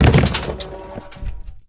anonHeadbutt.ogg